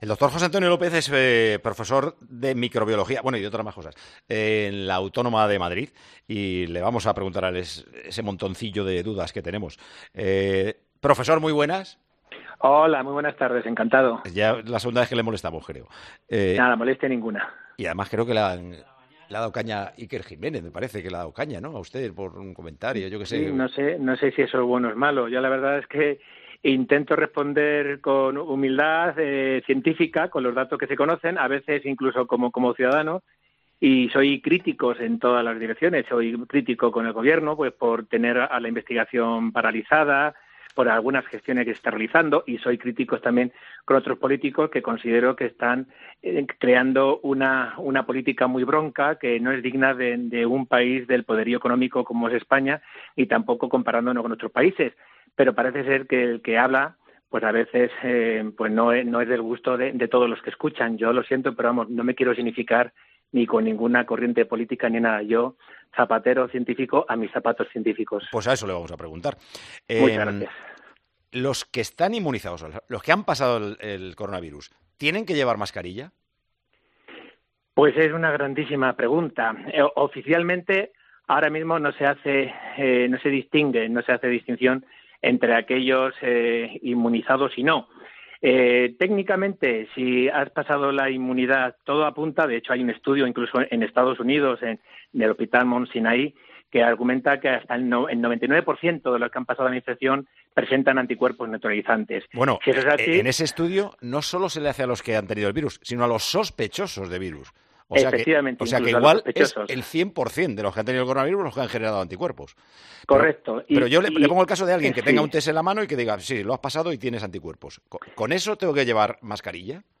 El Doctor y profesor de microbiología